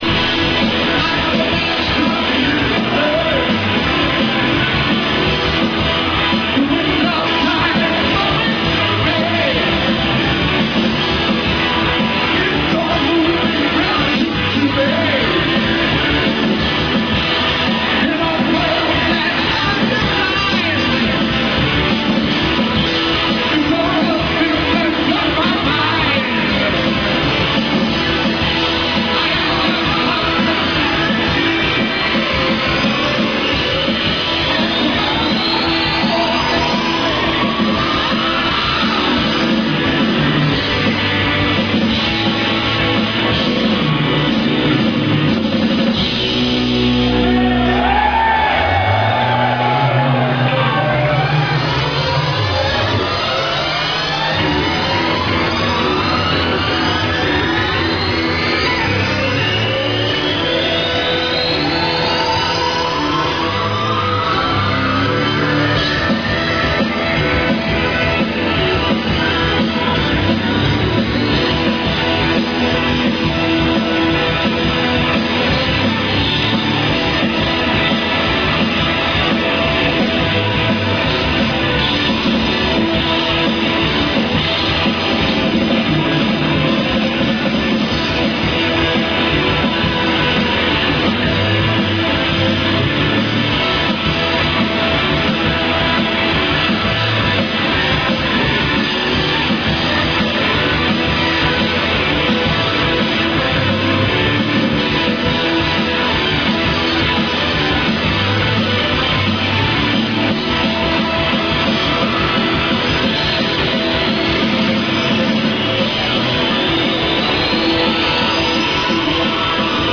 May 26th 2003  BRISTOL - Academy